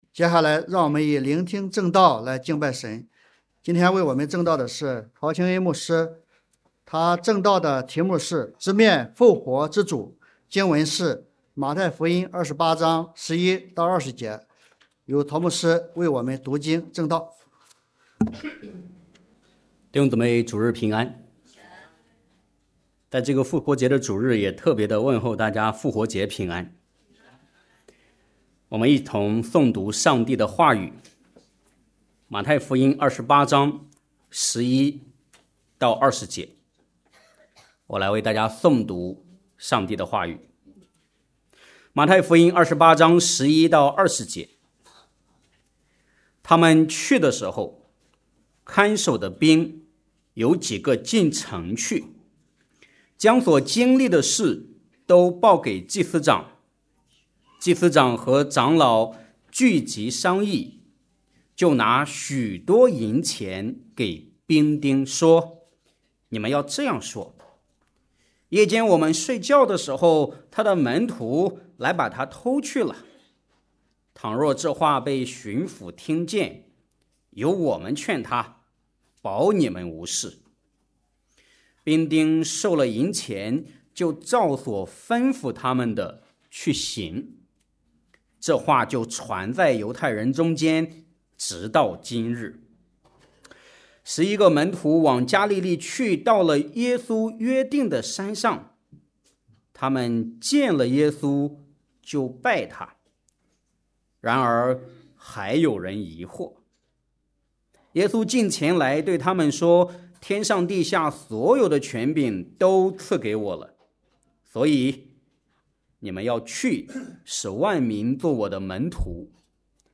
复活节证道：直面复活之主！
复活节证道：直面复活之主.mp3